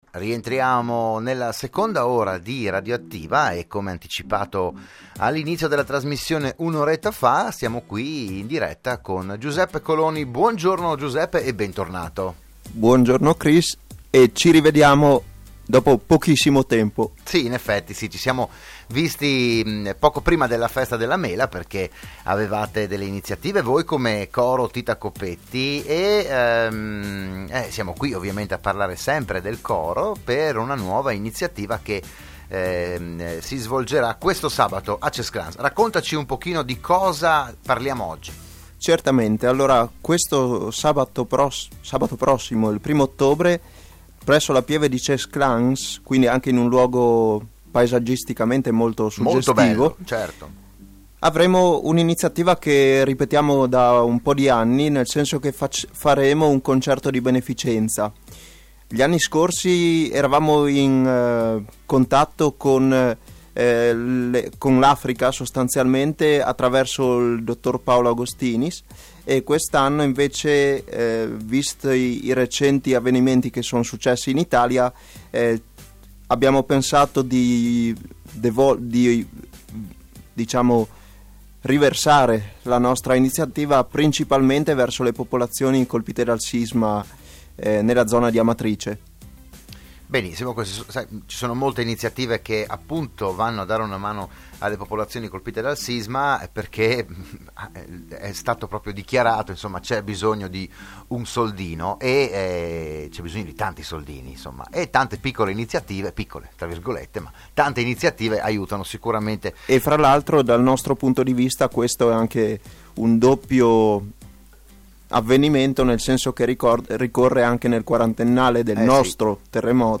Proponiamo il podcast dell’intervento